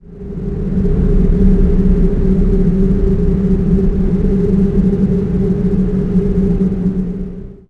WIND2LO.WAV